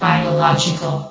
New & Fixed AI VOX Sound Files
biological.ogg